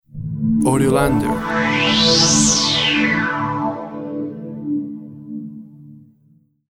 Simple musical logo with synthesized sounds.
Tempo (BPM) 100